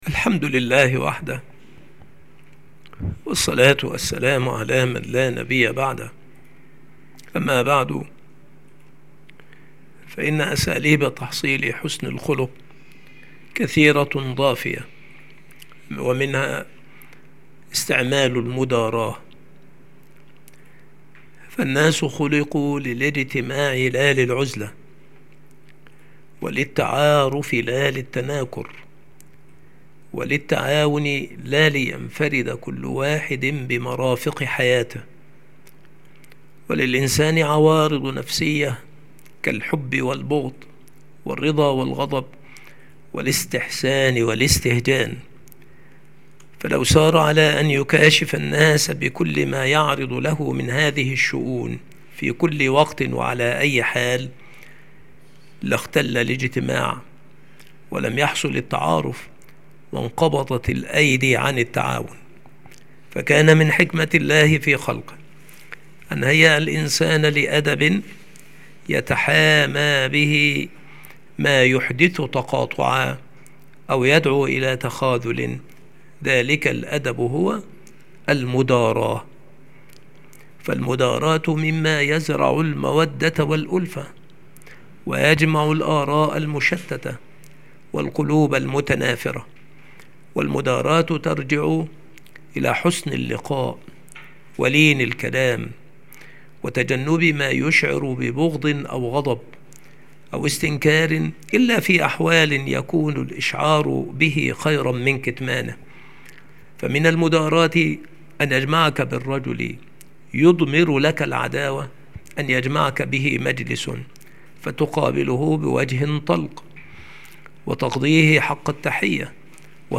مكان إلقاء هذه المحاضرة المكتبة - سبك الأحد - أشمون - محافظة المنوفية - مصر عناصر المحاضرة : استعمال المداراة. لزوم الصدق. تجنب كثرة اللوم والتعنيف على من أساء. تجنب الوقيعة في الناس. مصاحبة الأخيار وأهل الأخلاق الفاضلة. توطين النفس على الاعتدال حال السراء والضراء. معرفة أحوال الناس ومراعاة عقولهم ومعاملتهم بمقتضى ذلك.